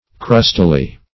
Search Result for " crustily" : The Collaborative International Dictionary of English v.0.48: Crustily \Crust"i*ly\ (kr[u^]st"[i^]-l[y^]), adv.
crustily.mp3